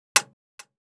Index of /traerlab/AnalogousNonSpeech/assets/stimuli_demos/repeated_impact/small_plastic_longthin_fork
drop04_back.wav